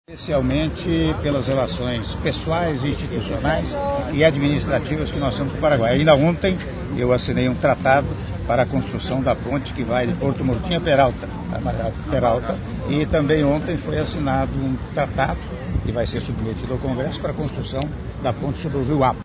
Áudio Entrevista coletiva concedida pelo Presidente da República, Michel Temer, durante Cerimônia de Transmissão do Mandato Presidencial - Assunção/Paraguai- (19s)